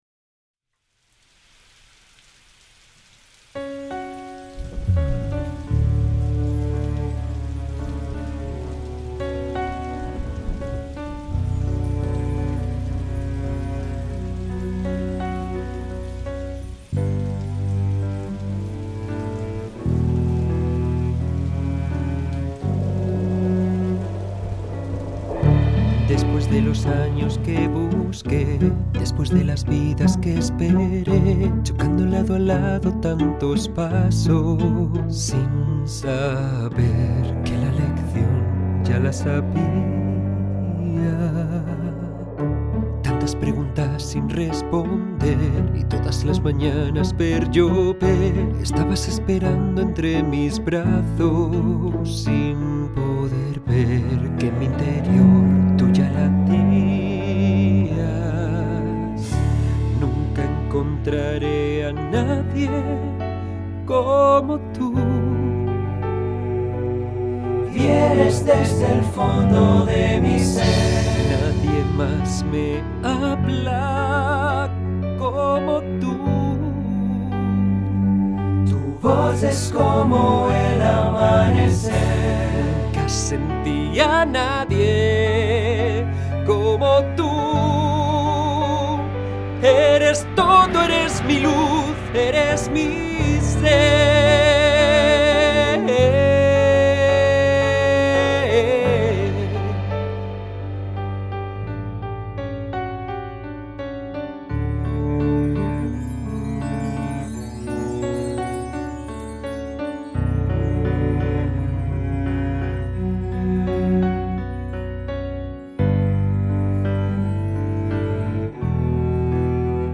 Cantante – Actor – Vocal Coach
Esta grabación es solo una maqueta